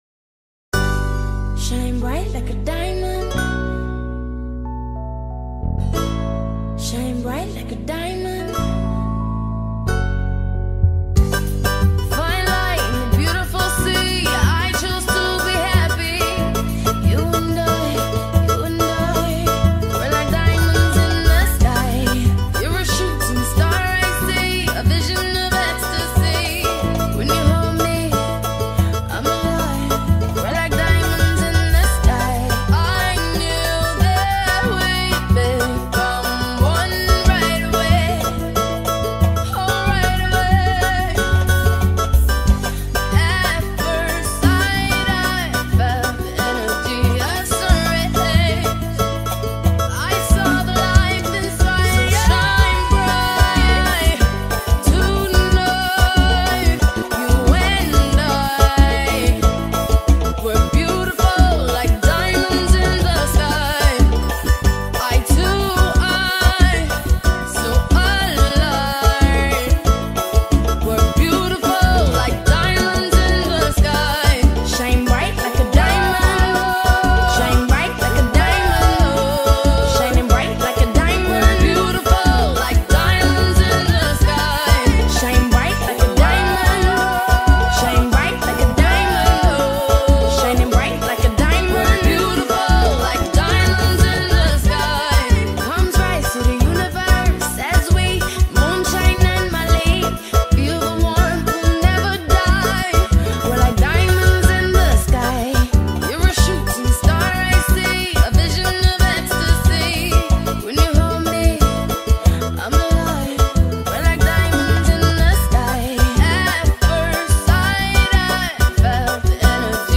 2024-08-21 23:25:43 Gênero: Pagode Views